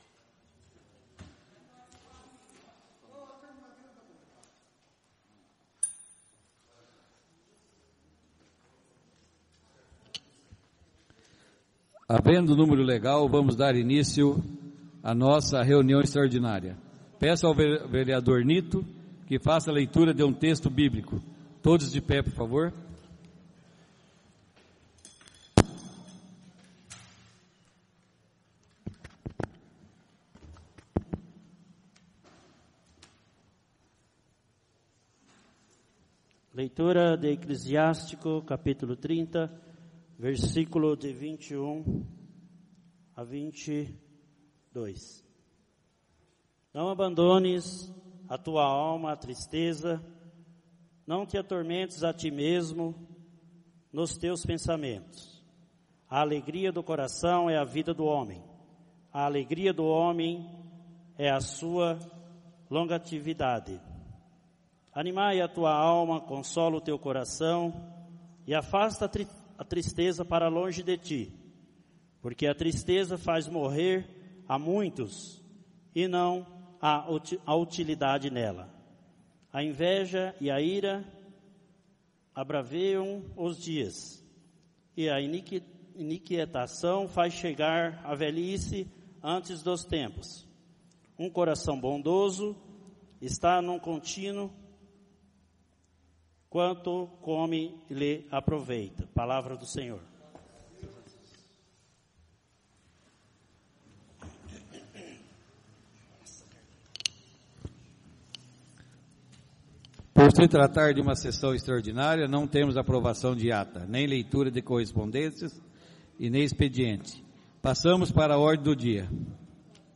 O Presidente da Câmara Municipal de Sarandi-Pr Sr. Carlos Roberto Falaschi, verificando a existência de quórum legal dá início à 5ª Reunião Extraordinária do dia 23/05/2017.
A convite do Senhor Presidente, o edil JOSÉ APARECIDO DA SILVA procedeu à leitura de um texto bíblico.